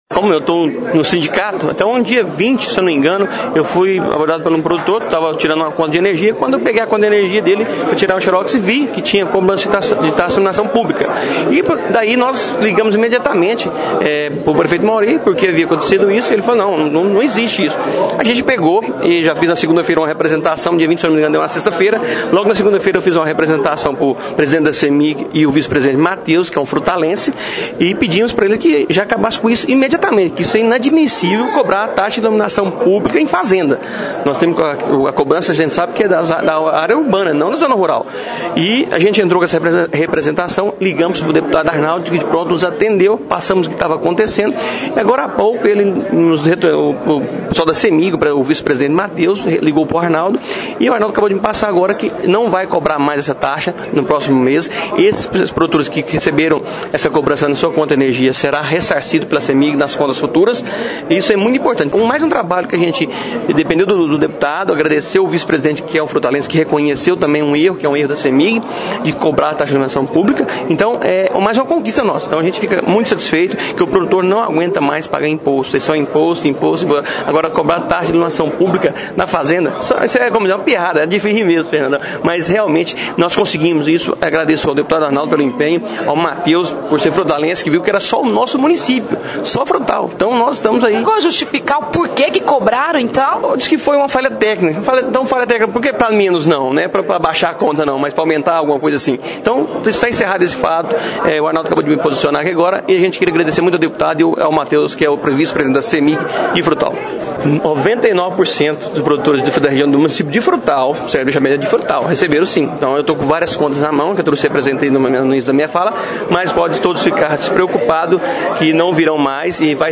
Diversas queixas chegaram ao conhecimento de autoridades ligadas à área rural reclamando que estavam recebendo cobrança de Taxa de Iluminação Pública na zona rural. (Clique no player e ouça a entrevista).